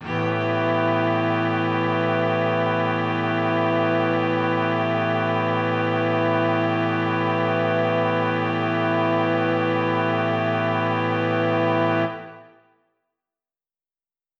SO_KTron-Cello-A7:9.wav